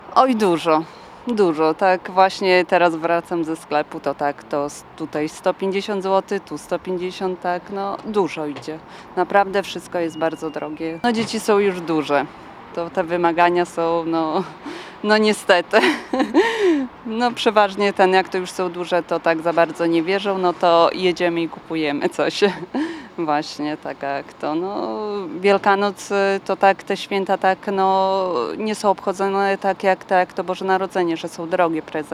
Przeznaczymy mniej pieniędzy na Wielkanoc niż na Boże Narodzenie. Tak o wydatkach na zbliżające się święta mówią zapytani przez nas suwalczanie. Większość osób, z którymi rozmawialiśmy mówiła, że trudno podać konkretne kwoty.